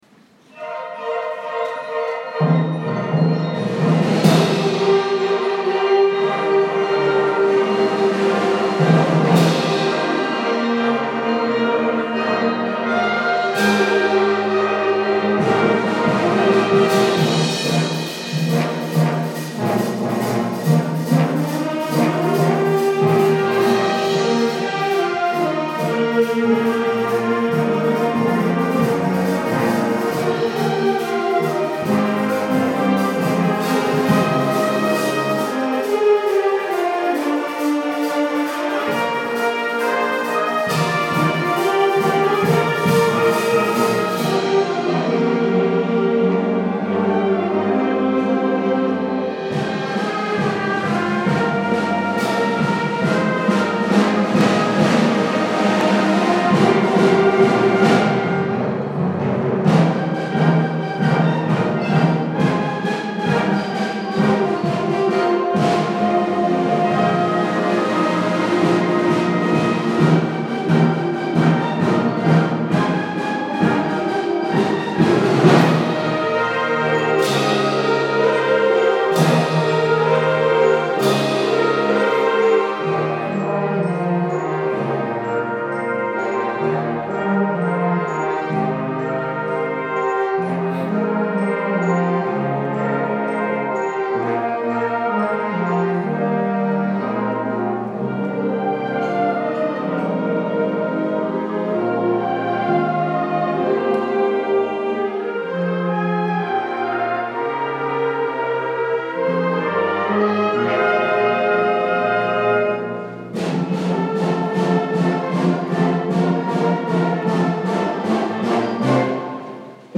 Dashing Through the Snow - Concert Band